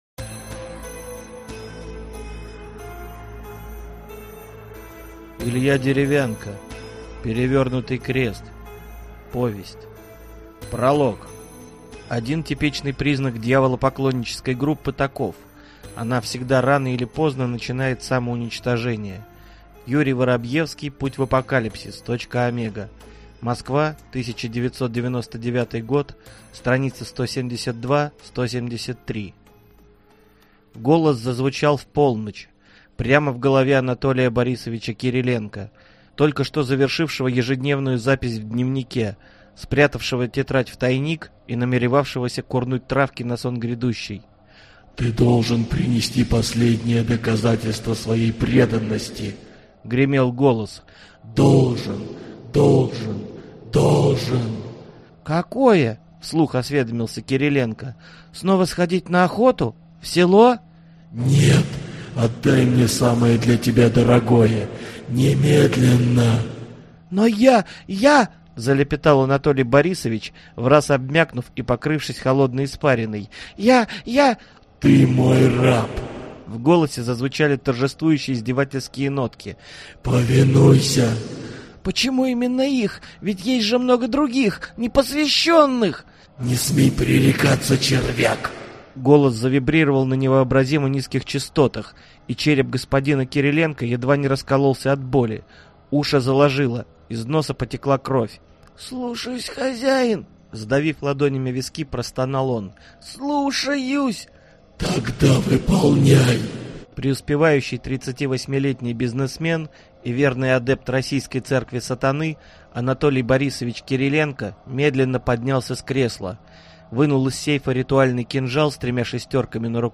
Аудиокнига Перевернутый крест | Библиотека аудиокниг